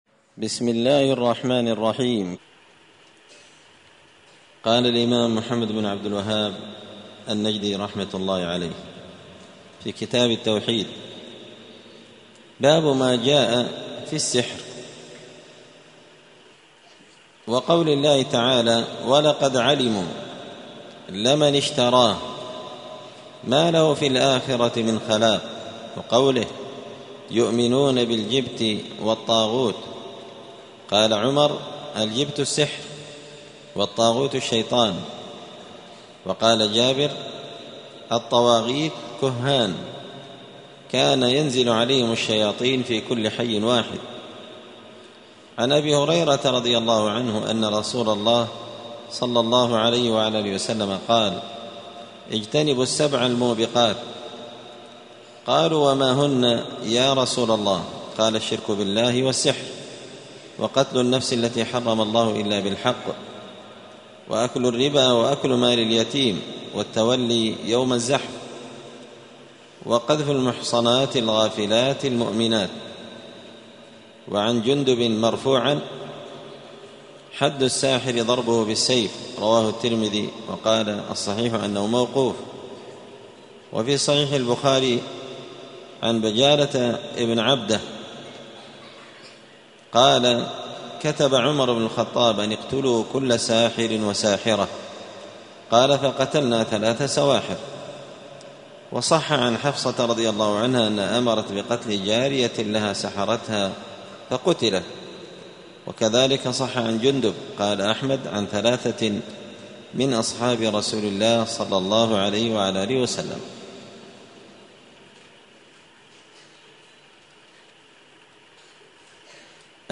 دار الحديث السلفية بمسجد الفرقان قشن المهرة اليمن
*الدرس السابع والستون (67) {باب ماجاء في السحر}*